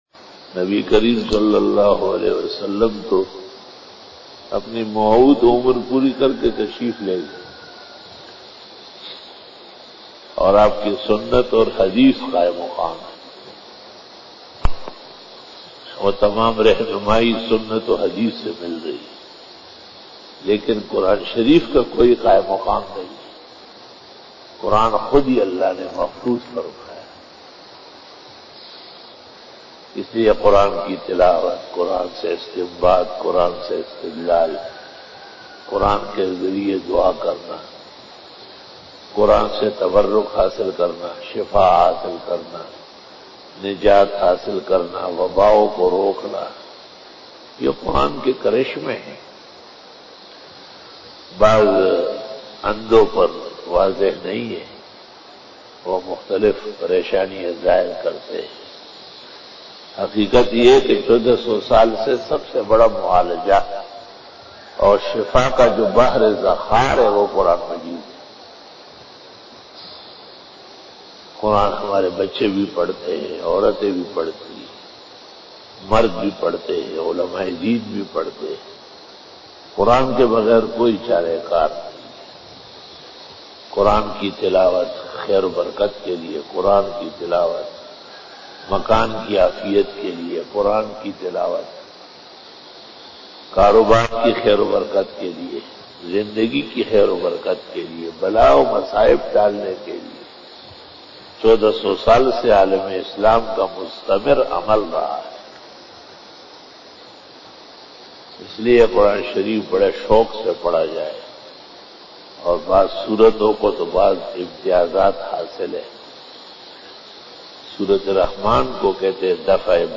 After Namaz Bayan
After Fajar Byan